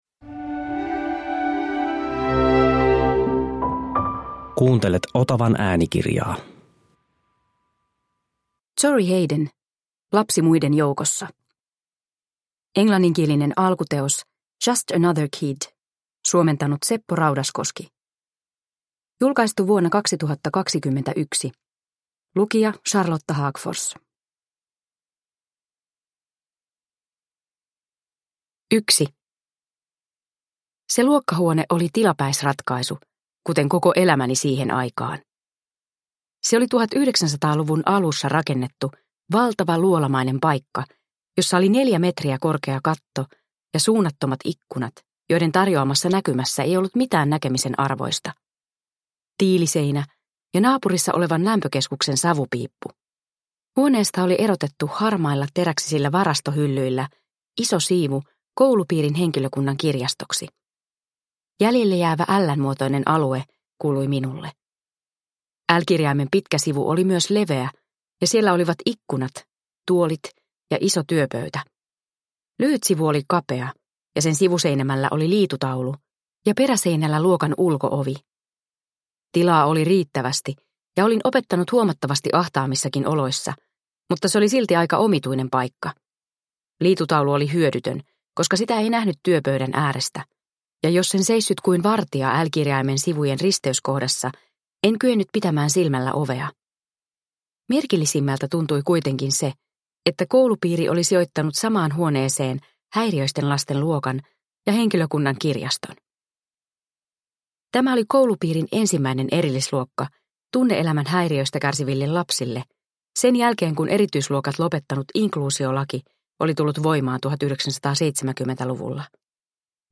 Lapsi muiden joukossa – Ljudbok – Laddas ner